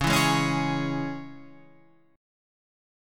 C# Minor